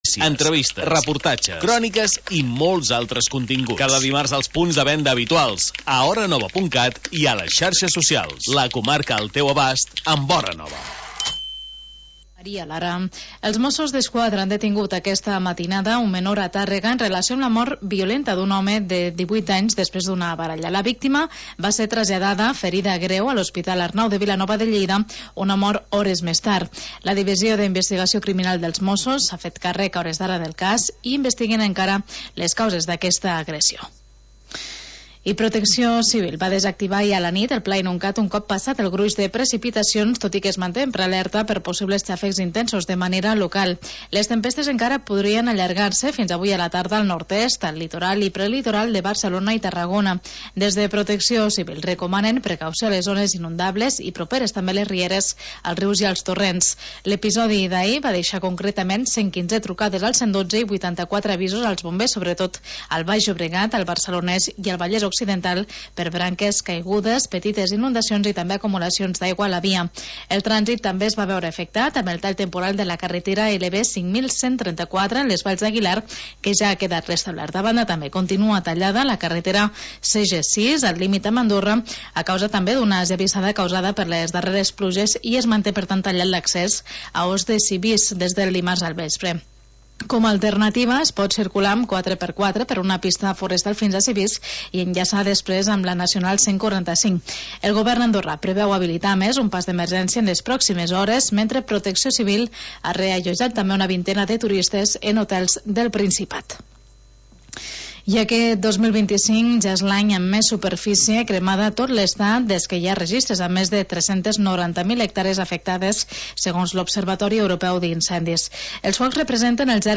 Magazín territorial d'estiu